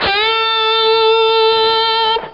A Bend Up Sound Effect
a-bend-up.mp3